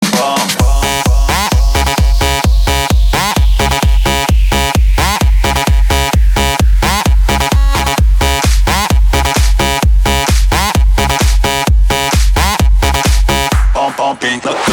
house
клубные